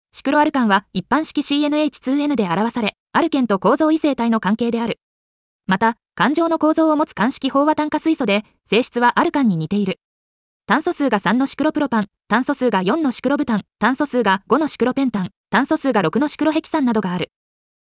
• 耳たこ音読では音声ファイルを再生して要点を音読します。
ナレーション 音読さん